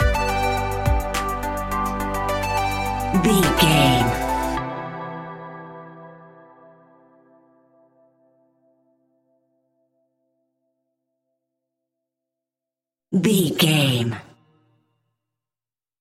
Aeolian/Minor
hip hop
hip hop instrumentals
chilled
laid back
groove
hip hop drums
hip hop synths
piano
hip hop pads